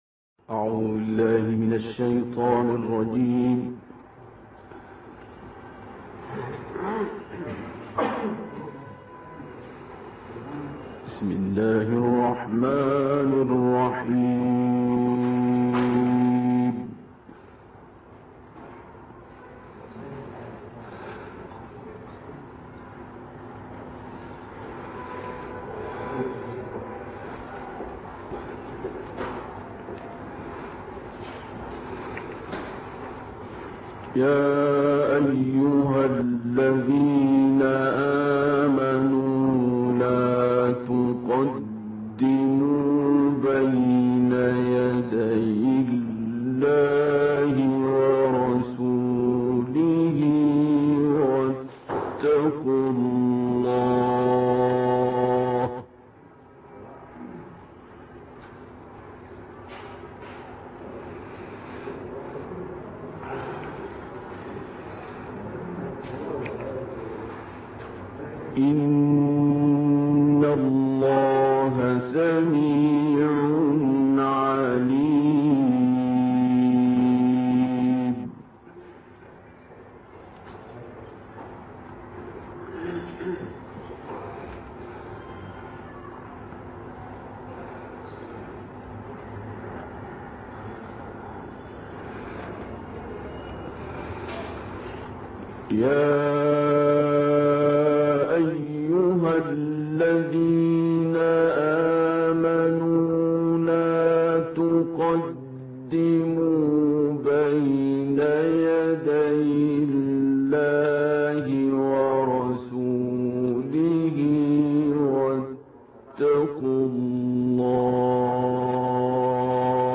صوت | تلاوت عبدالباسط از سوره «حجرات»
تلاوت شنیدنی استاد عبدالباسط عبدالصمد از سوره «حجرات» تقدیم مخاطبان ایکنا می‌شود.